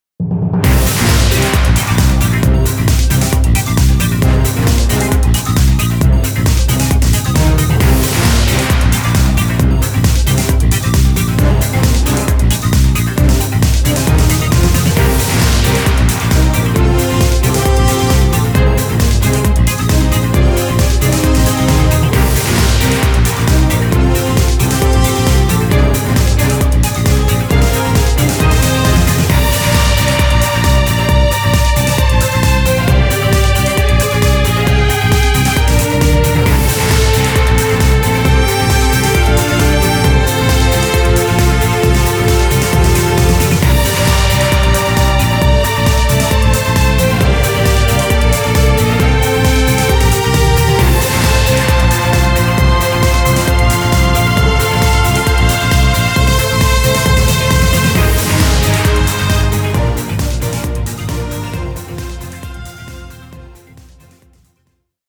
■ Background Music